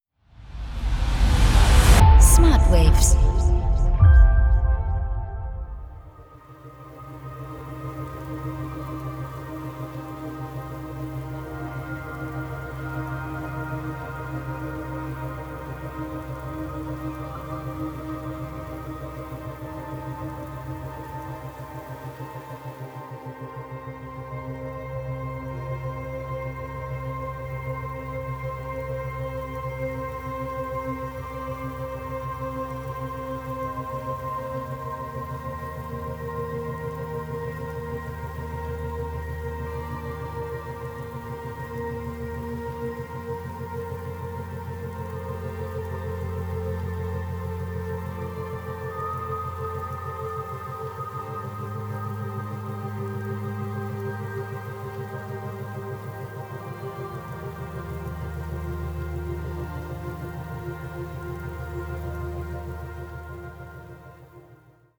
4-8 Hertz Theta Wellen Frequenzen